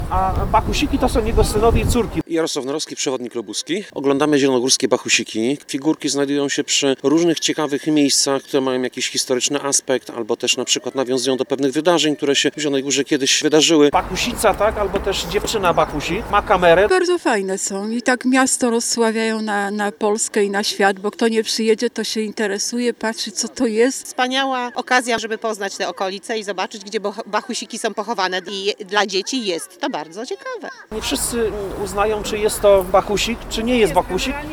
O 10:30 spod pomnika Bohaterów ponad 60 osób, w tym ponad 40 przedszkolaków, wyruszyło na spacer z przewodnikiem. Uczestnicy podążali szlakiem najbardziej charakterystycznych Bachusików.